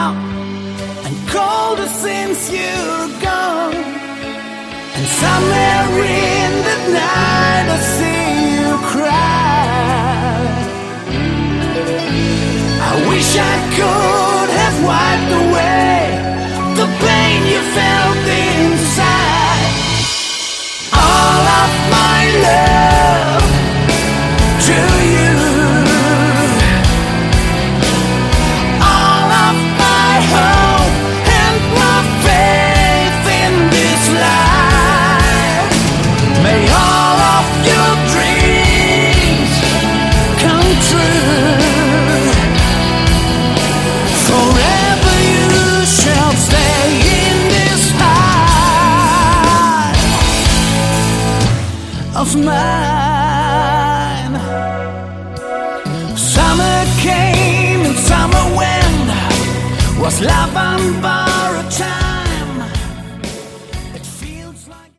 Category: AOR
Lead Vocals
Guitars
Bass
Drums